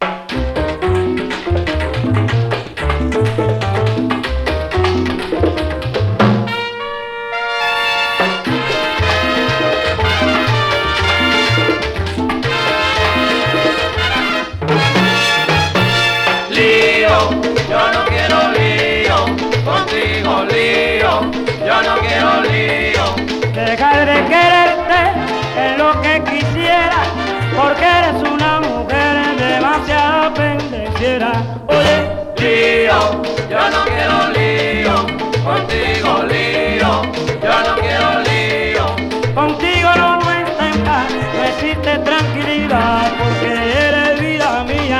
Latin, World　USA　12inchレコード　33rpm　Mono